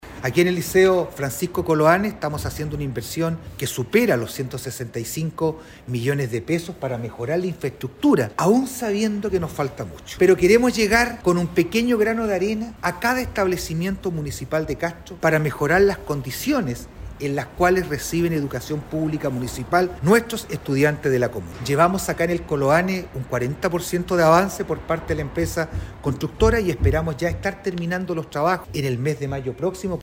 Al respecto la primera autoridad comunal indicó: